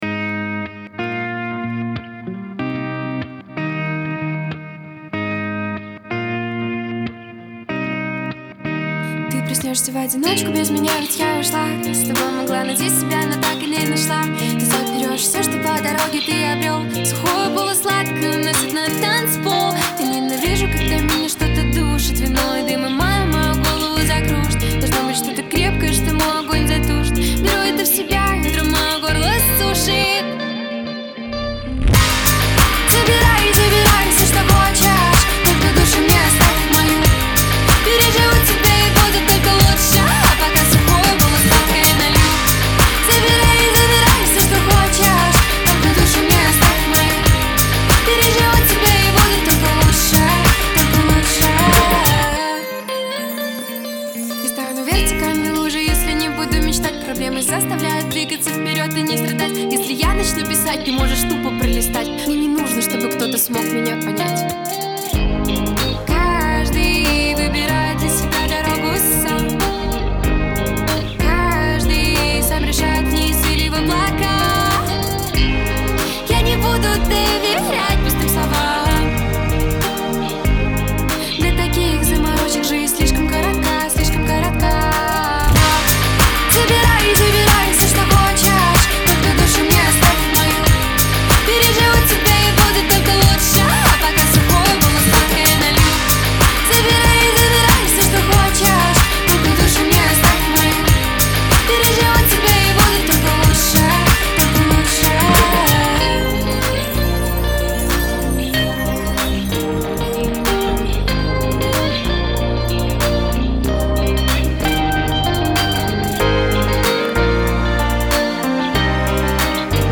яркая поп-песня